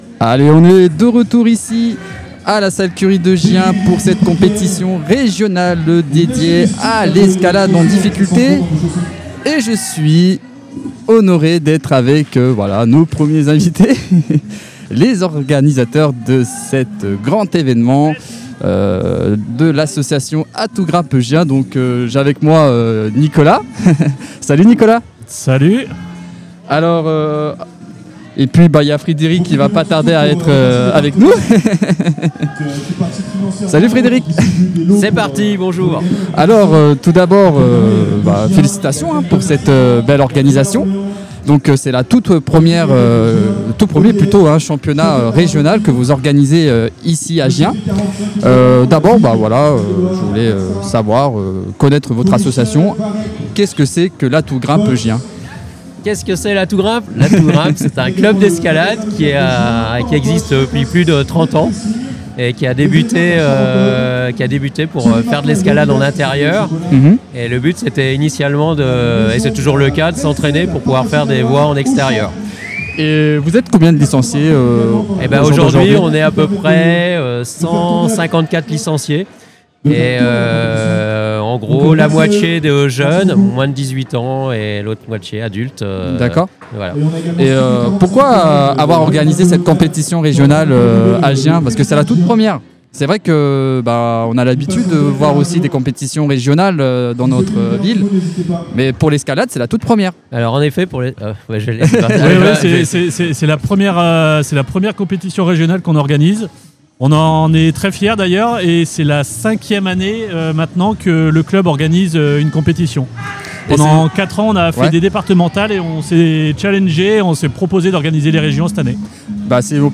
Un échange authentique pour mieux comprendre le rôle d’un club local dans le développement de l’escalade, mais aussi l’importance de ce type de compétition pour la ville de Gien et ses habitants.